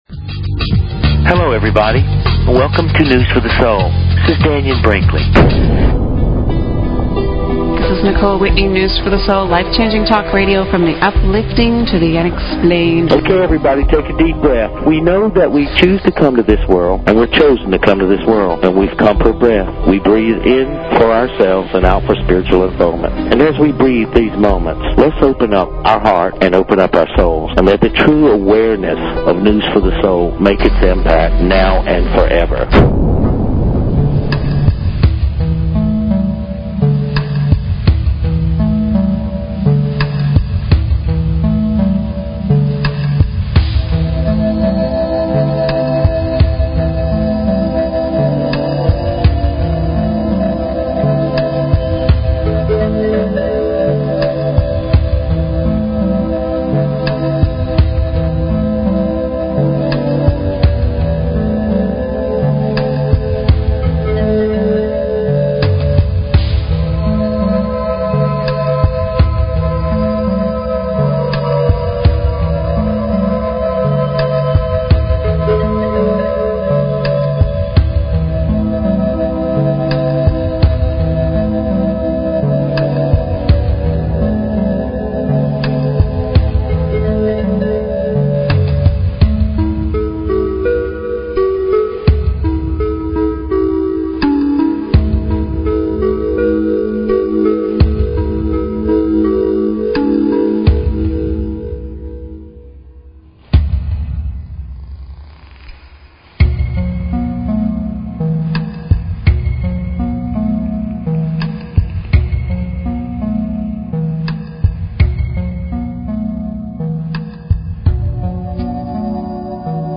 Talk Show Episode, Audio Podcast, News_for_the_Soul and Courtesy of BBS Radio on , show guests , about , categorized as
LIVE ON AIR GROUP HEALINGS!